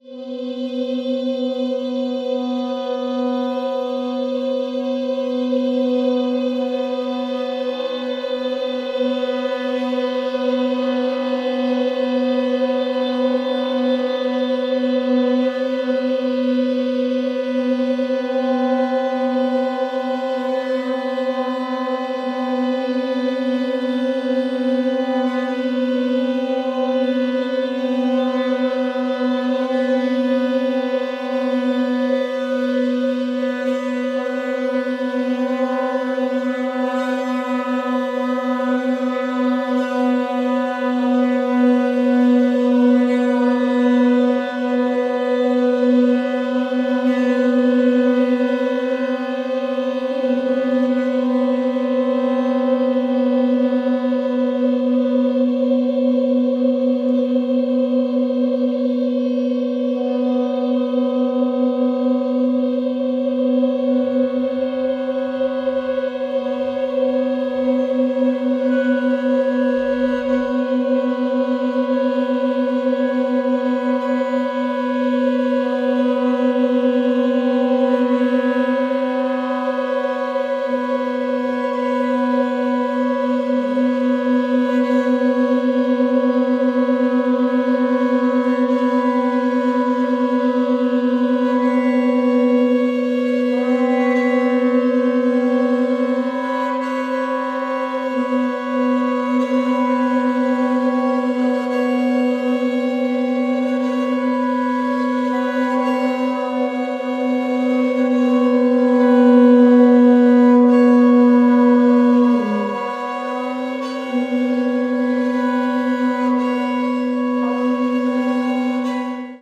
piano
voice
trpt
tuba